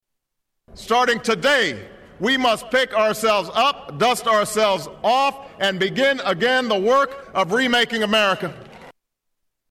Tags: Famous Inaguration clips Inaguration Inaguration speech President Obama